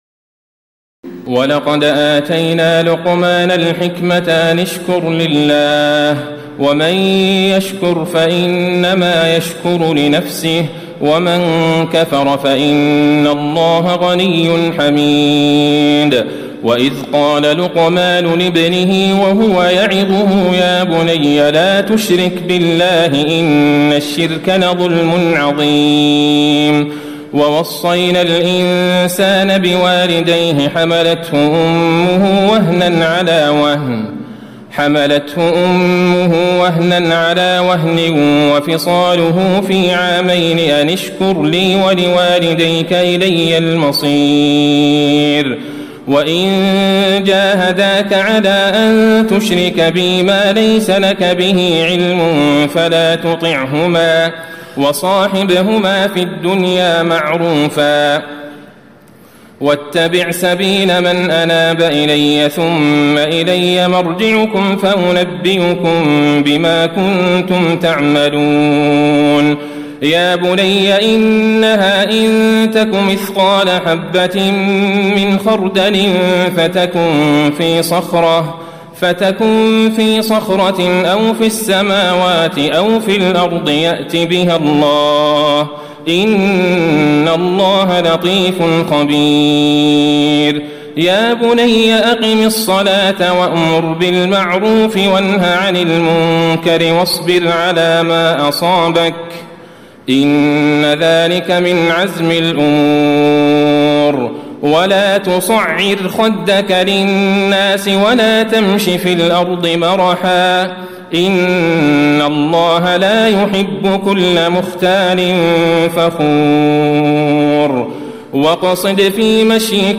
تراويح الليلة العشرون رمضان 1436هـ من سور لقمان (12-34) والسجدة و الأحزاب (1-34) Taraweeh 20 st night Ramadan 1436H from Surah Luqman and As-Sajda and Al-Ahzaab > تراويح الحرم النبوي عام 1436 🕌 > التراويح - تلاوات الحرمين